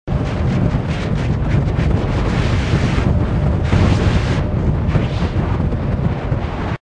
atmospheric_buffeting.wav